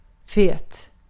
Recordings and example transcriptions in this help are in Sweden Swedish, unless otherwise noted.
fet mayor